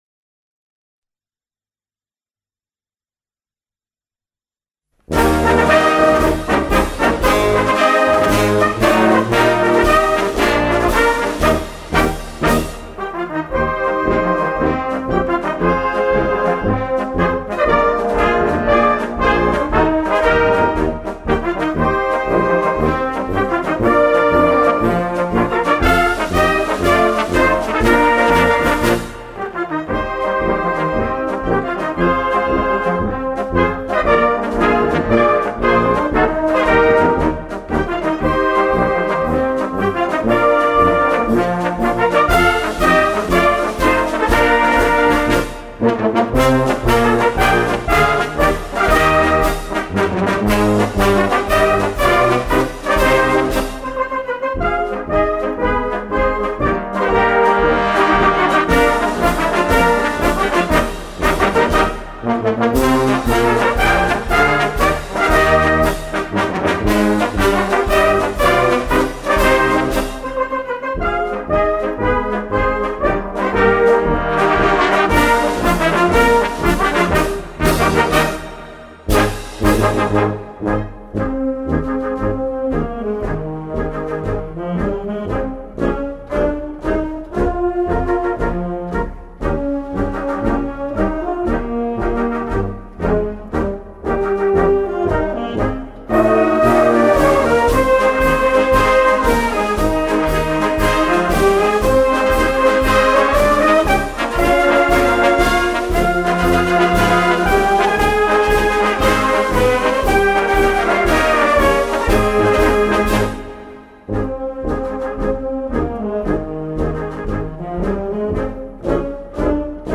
Gattung: Straßenmarsch
Besetzung: Blasorchester